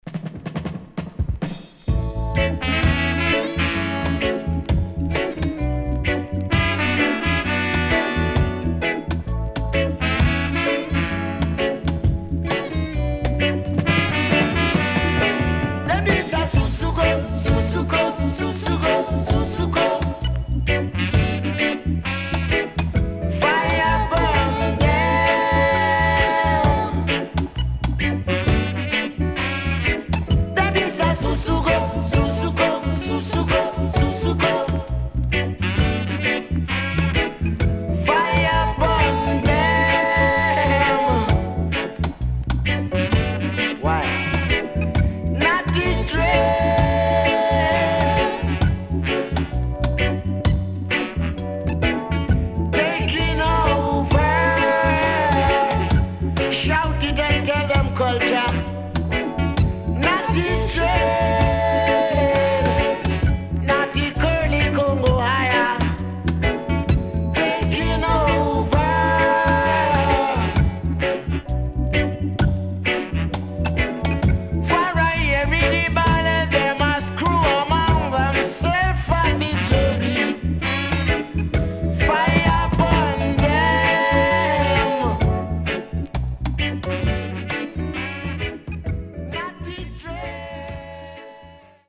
SIDE B:少しジリジリしたノイズ入りますが良好です。